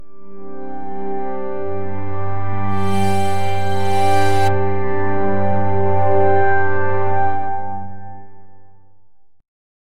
A gentle swoosh for a zoom transition. Smooth, warm sound.